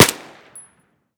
M16_sil-1.ogg